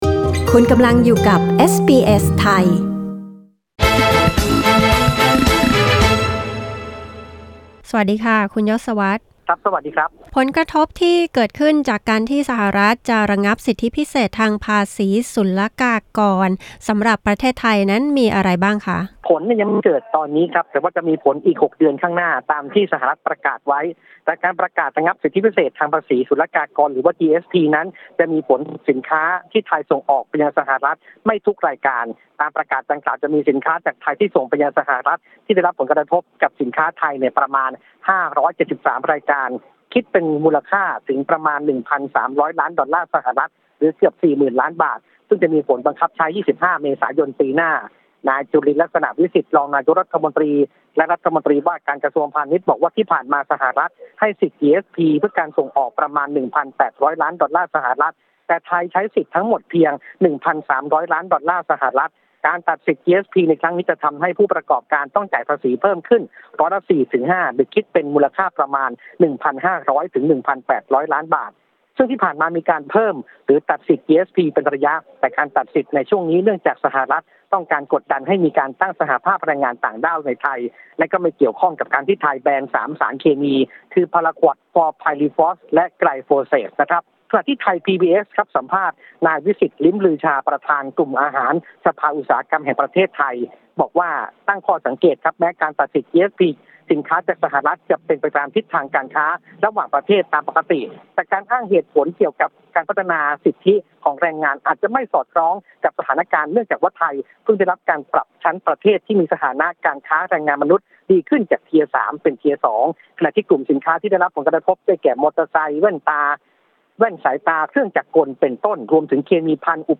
กดปุ่ม 🔊 ด้านบนเพื่อฟังรายงานข่าว